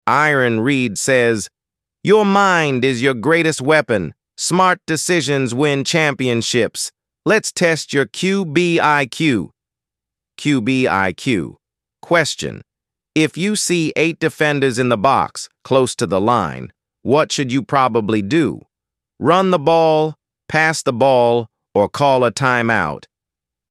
ElevenLabs_2026-01-27T16_39_16_Adam – Dominant, Firm_pre_sp89_s41_sb48_se0_b_m2